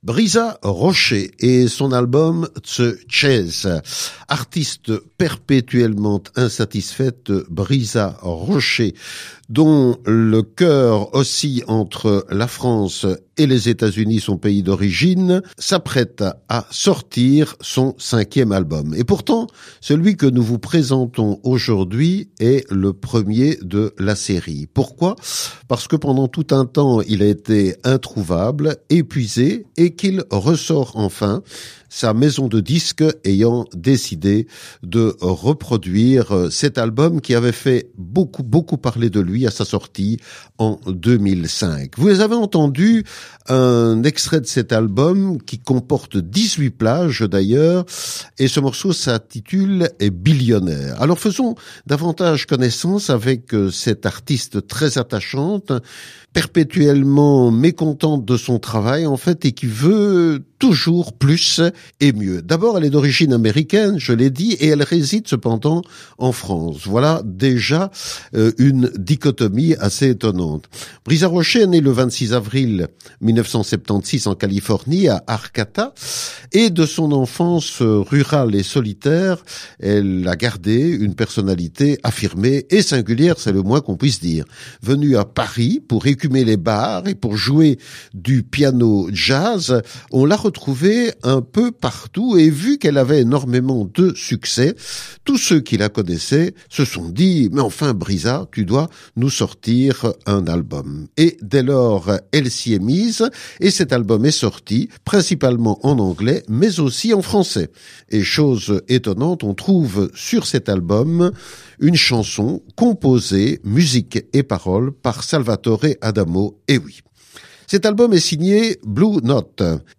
avec ses tonalités jazz.